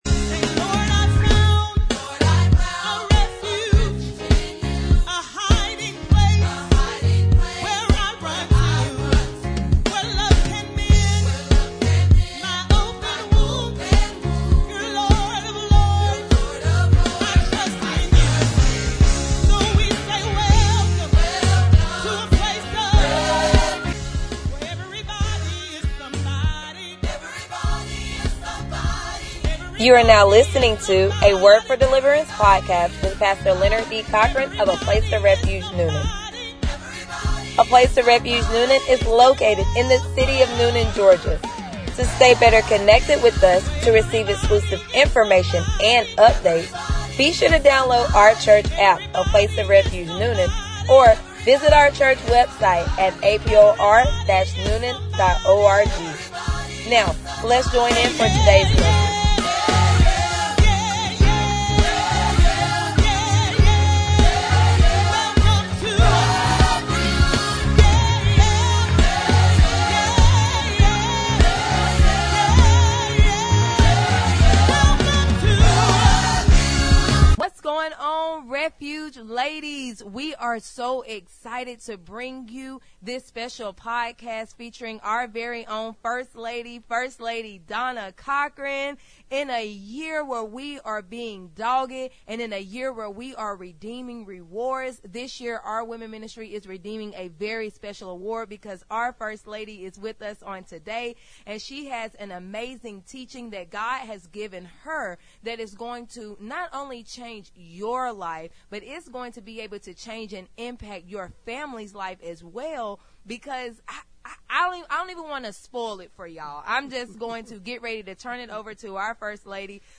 Sermons | A Place Of Refuge Newnan